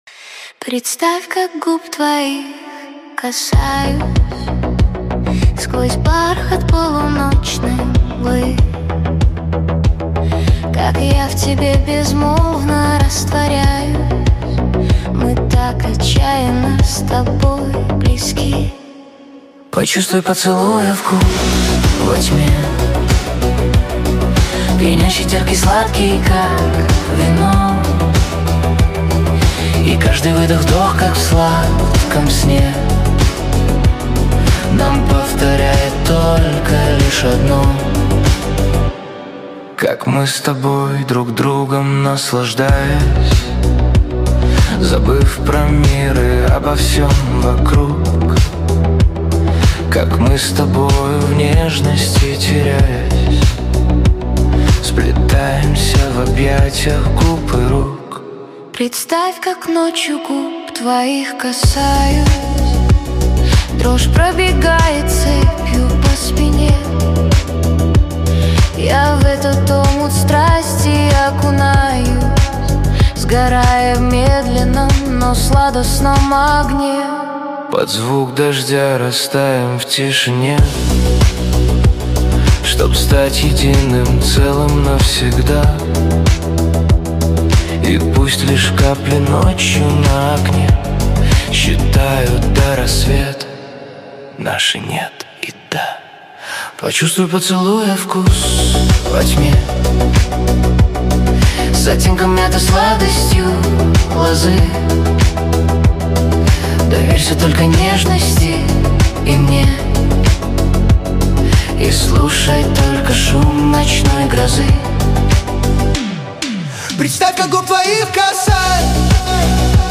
Красивый дуэт и чарующее исполнение!